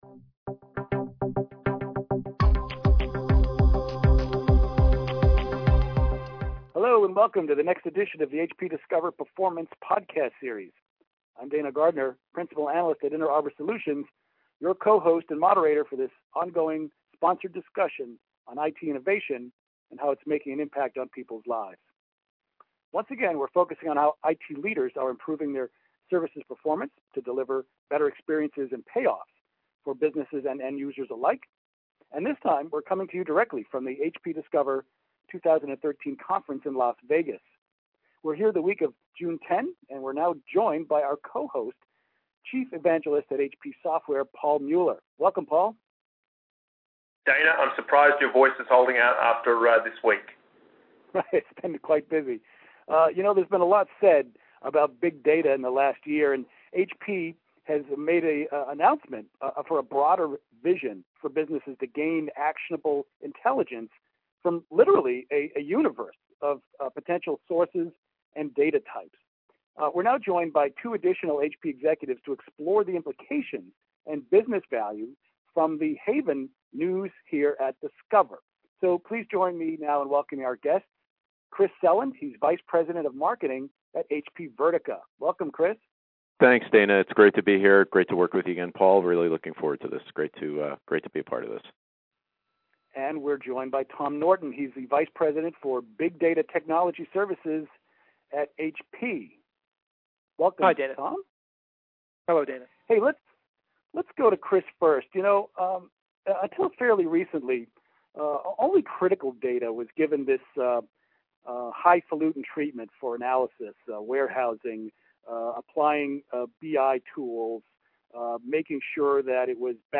Once again, we're focusing on how IT leaders are improving their services' performance to deliver better experiences and payoffs for businesses and end users alike, and this time we're coming to you directly from the HP Discover 2013 Conference in Las Vegas.